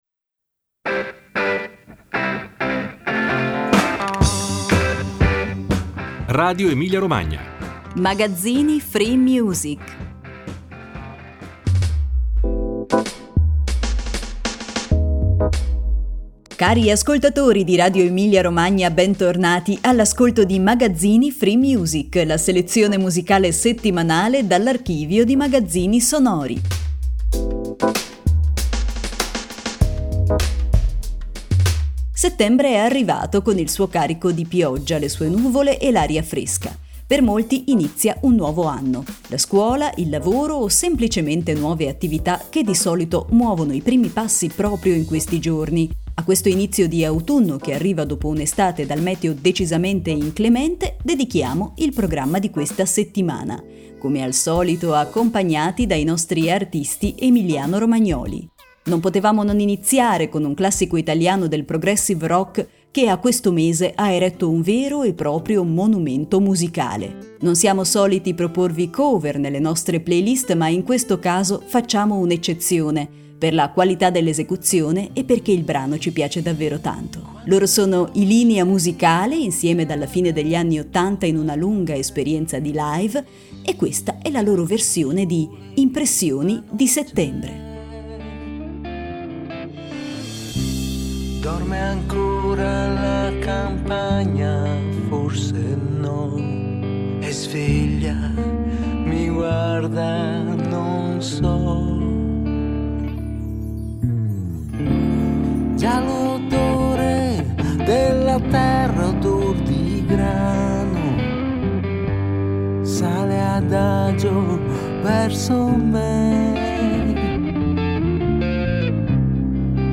standard jazz
tromba
pianoforte